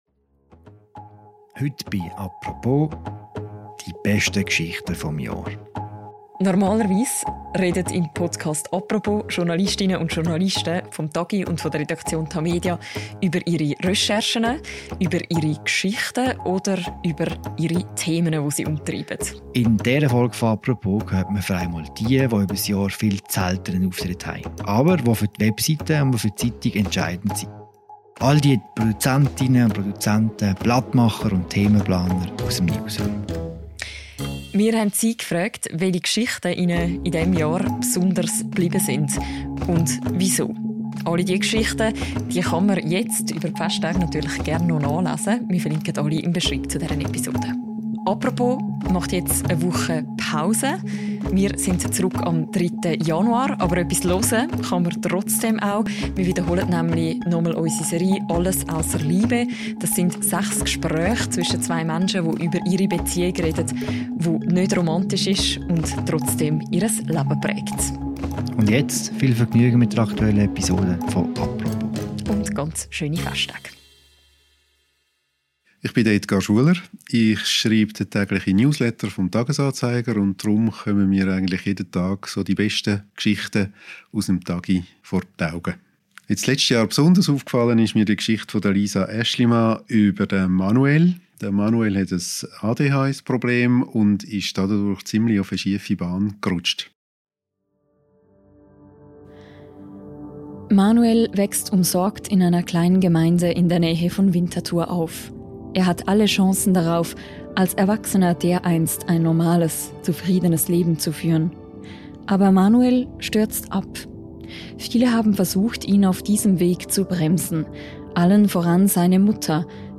In dieser Ausgabe von «Apropos» kommen fünf von ihnen zu Wort – und präsentieren ihre Geschichten des Jahres.